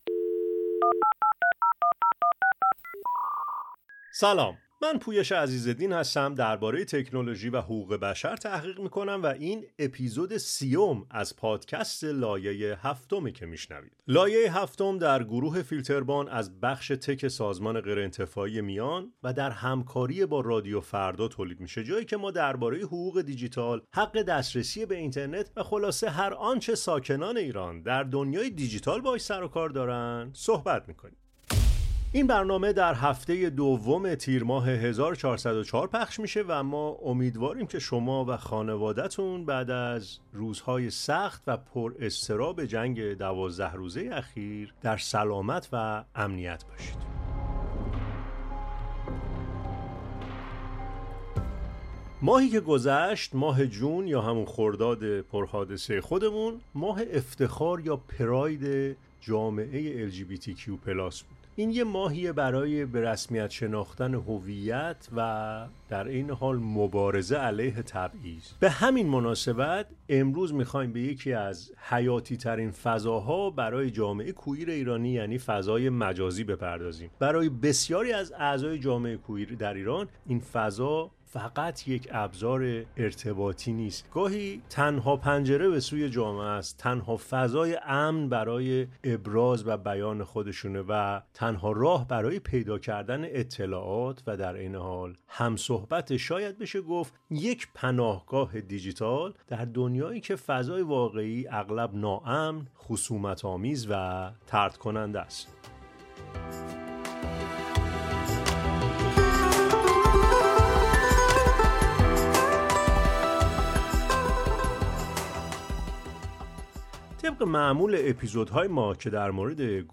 این گفتگو به موضوعات کلیدی مانند چالش‌های بیان هویت، خطرات «شبکه ملی اطلاعات» برای حریم خصوصی، تبعیض الگوریتمی و جنگ با دروغ‌پراکنی می‌پردازد و داستان‌های الهام‌بخش مقاومت و خلاقیت برای بقا را روایت می‌کند.